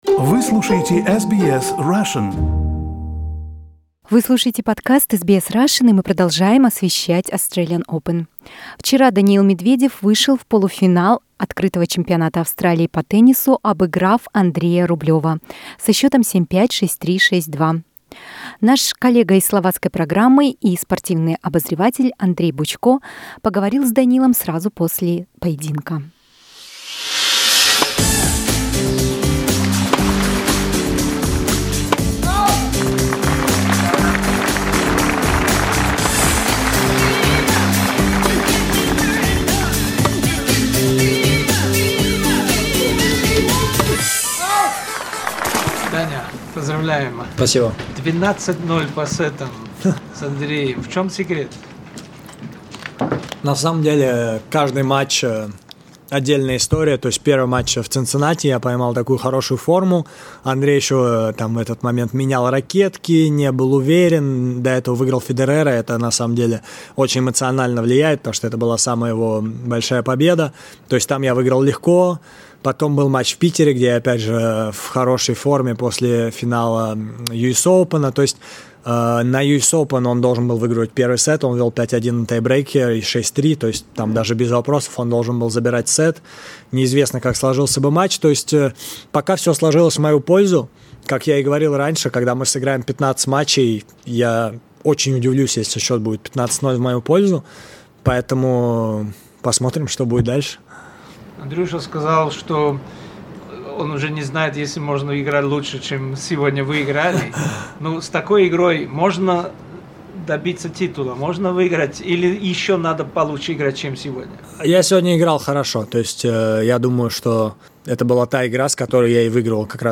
Interview with a Russian tennis player Daniil Medvedev at Australian Open 2021.